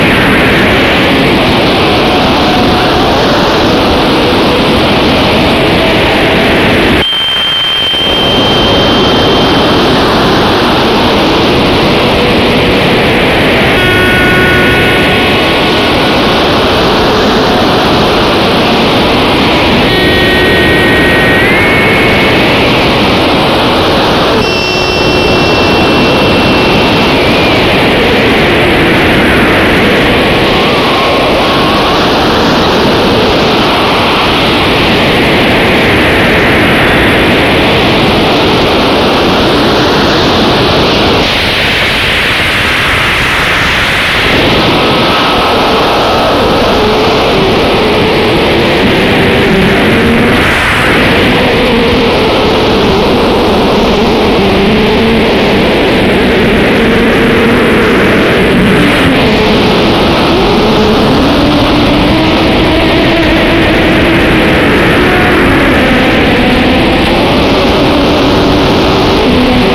Digitally transferred in 2024 from the original tapes.
Other instruments used were guitar,
rhythm box and many other noise generators.